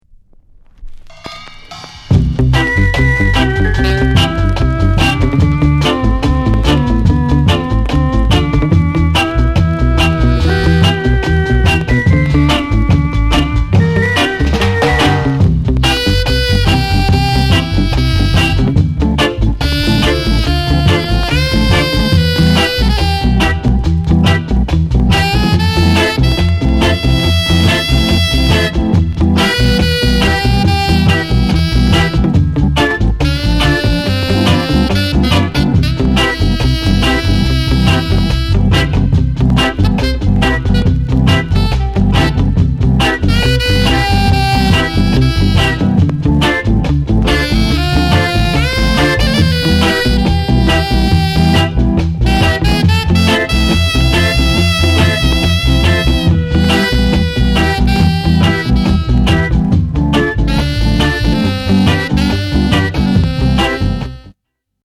GREAT SAX ROCKSTEADY INST